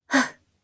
Kara-Vox_Landing_jp.wav